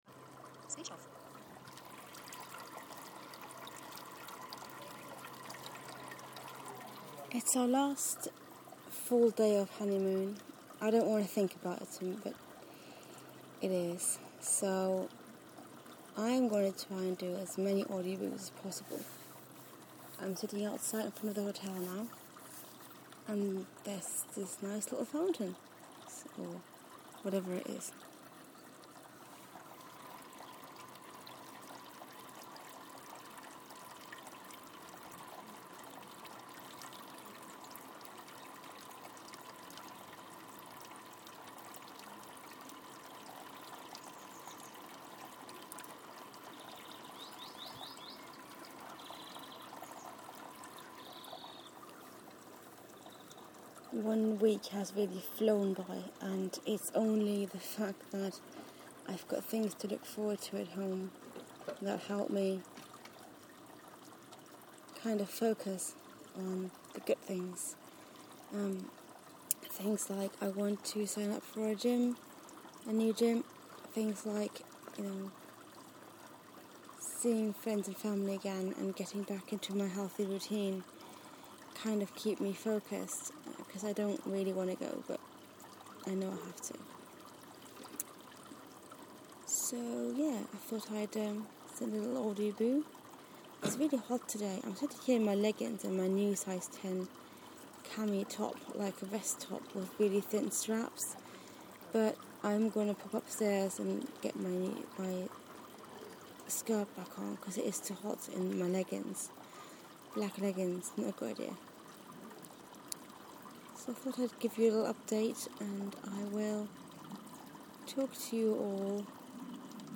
Fountain outside the hotel